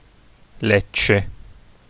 Lecce (/ˈlɛ/;[5] Italian: [ˈlettʃe]